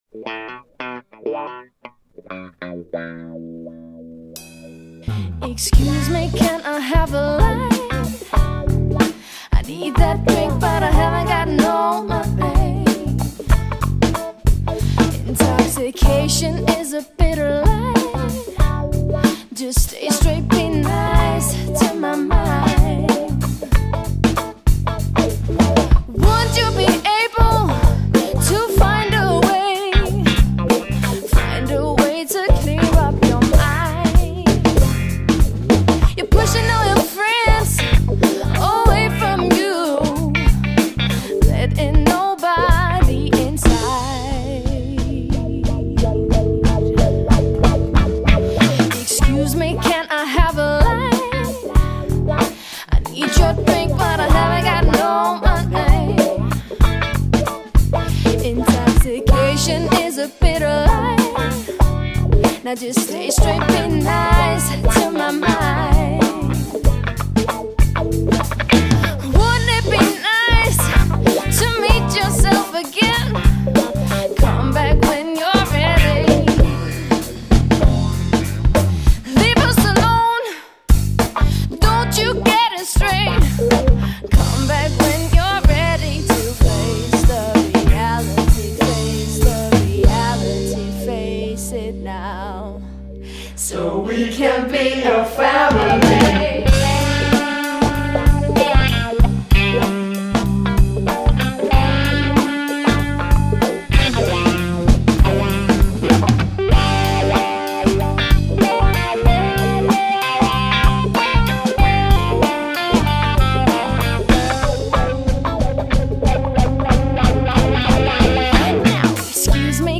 • Allround Partyband
• Coverband